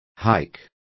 Complete with pronunciation of the translation of hike.